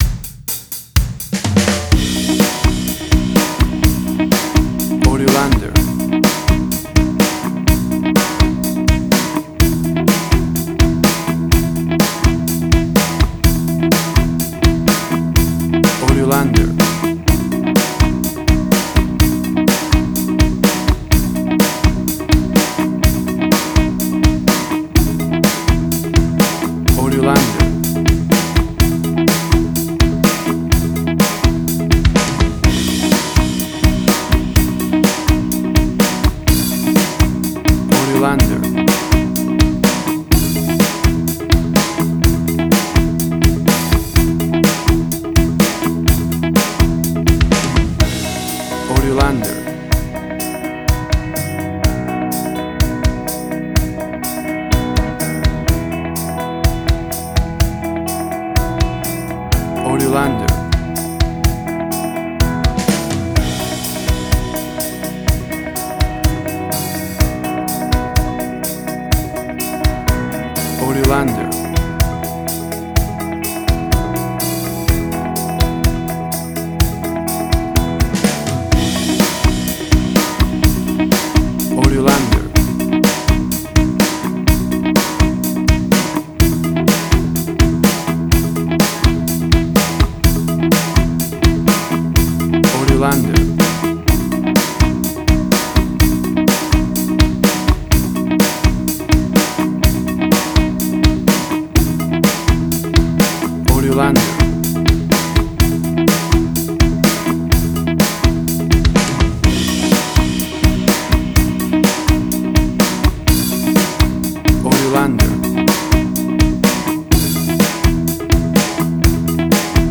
Tempo (BPM): 125